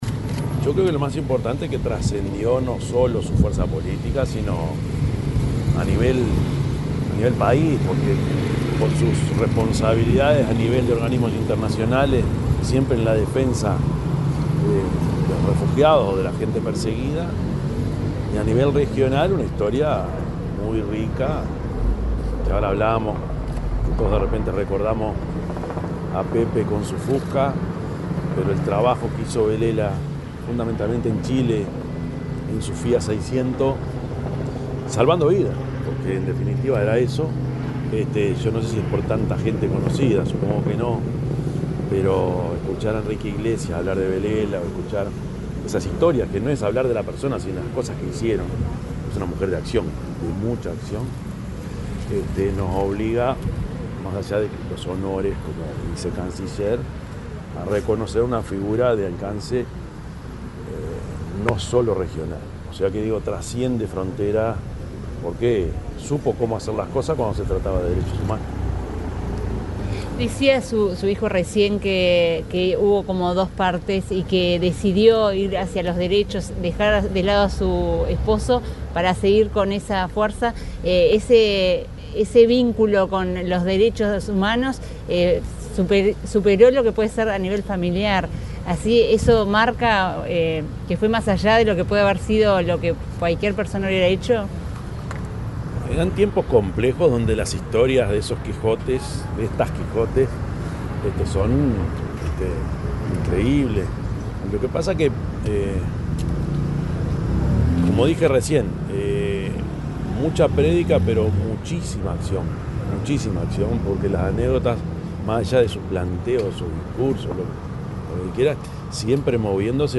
Declaraciones del presidente Yamandú Orsi, en recuerdo a Belela Herrera
El presidente de la República, profesor Yamandú Orsi, dialogó con la prensa, en recuerdo a Belela Herrera, al asistir a su velatorio, el domingo 18 de